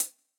IBI Closed Hat.wav